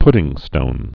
(pdĭng-stōn)